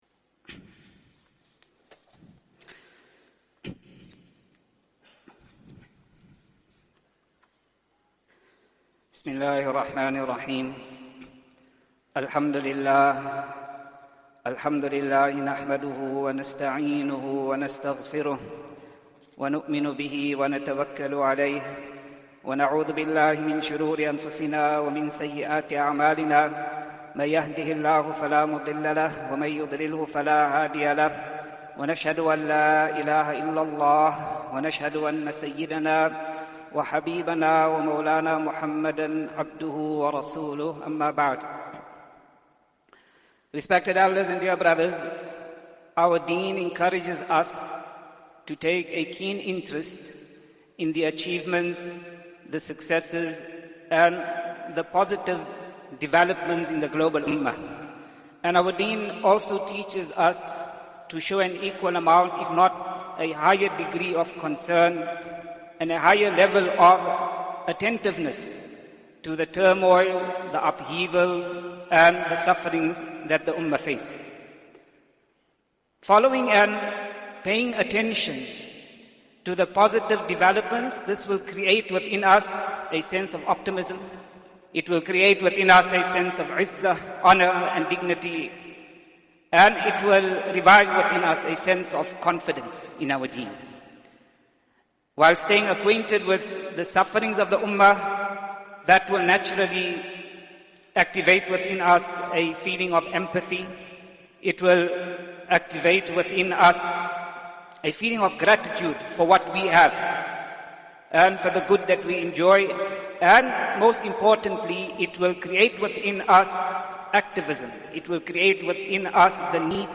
Easily listen to Islamic Lecture Collection